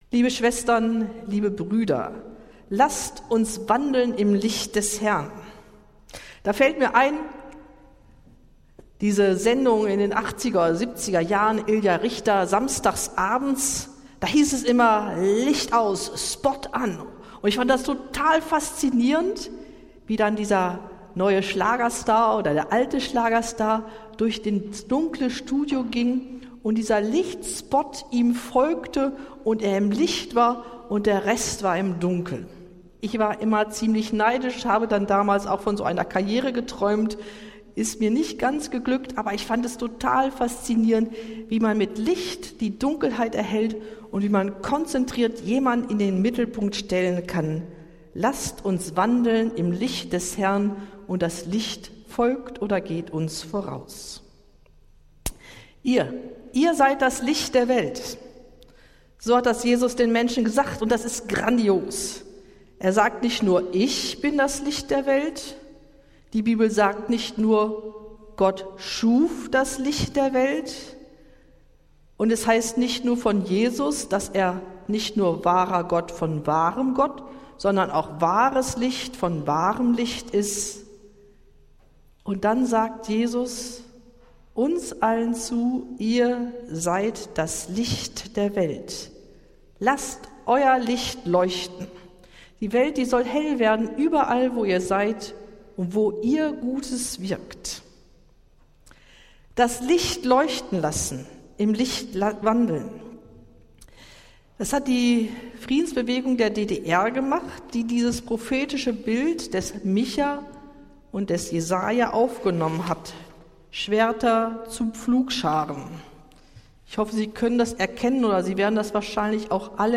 Predigt des Gottesdienstes aus der Zionskirche vom Sonntag, den 10. August 2025